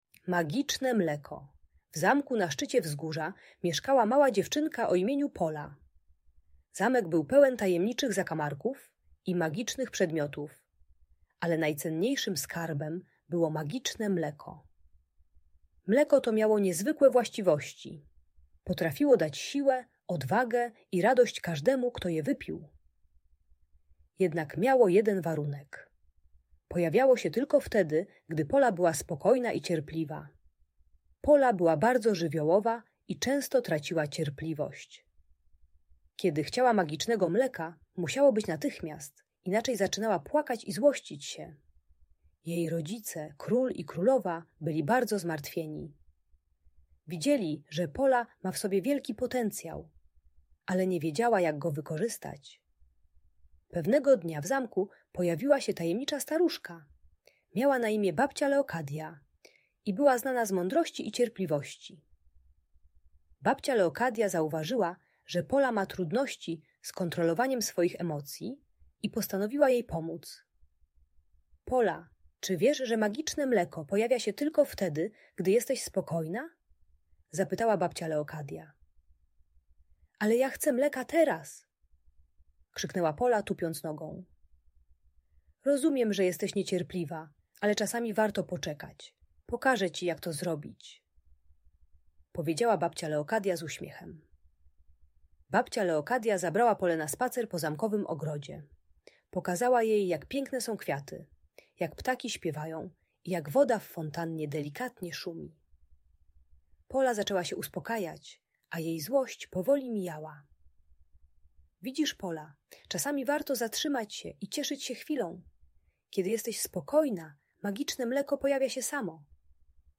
Magiczne Mleko - Audiobajka